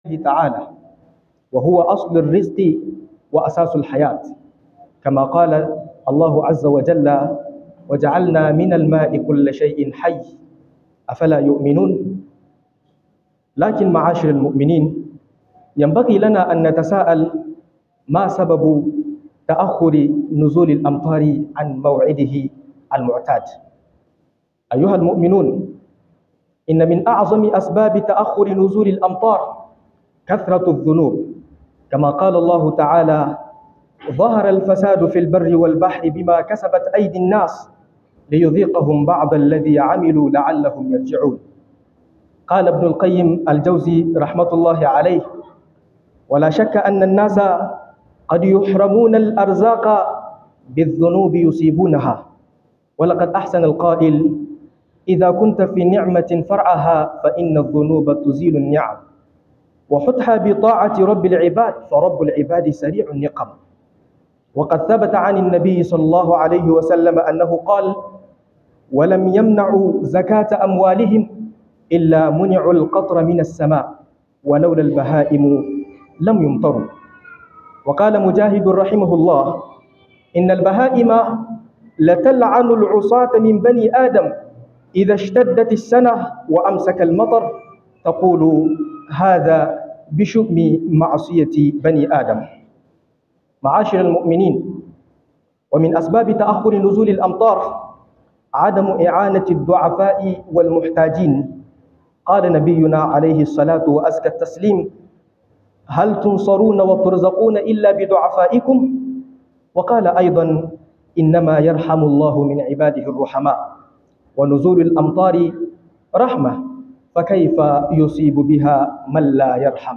Abunda ke jawo jinkirin ruwan sama - MUHADARA